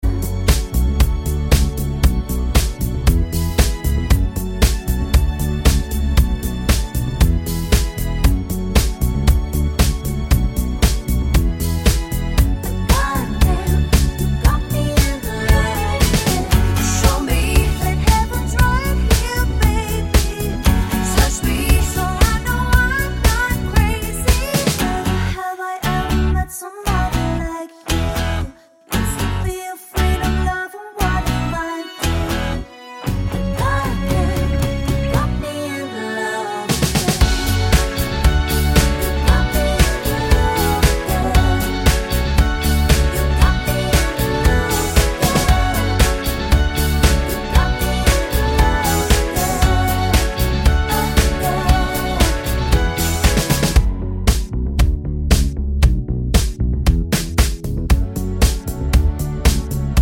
no Backing Vocals Pop (2020s) 4:17 Buy £1.50